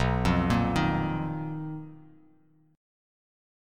BM7b5 Chord
Listen to BM7b5 strummed